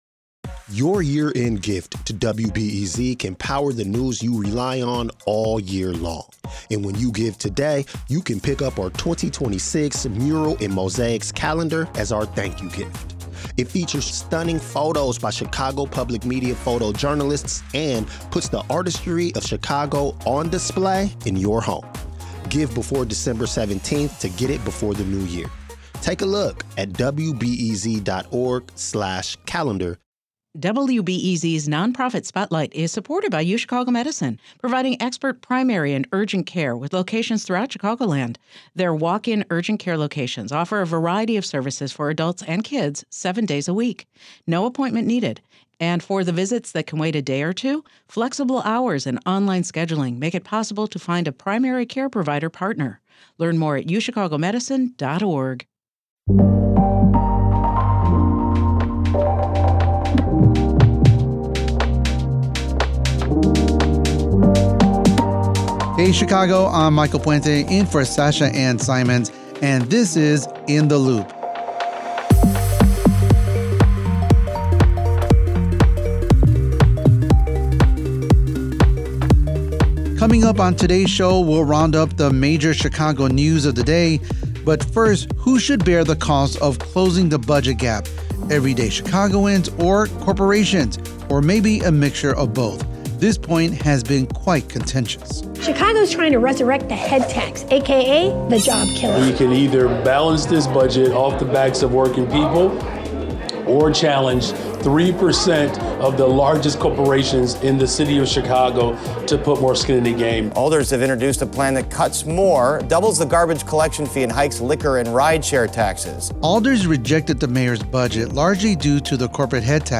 … continue reading 3141 episoder # WBEZ Chicago # News Talk # News # On Covid19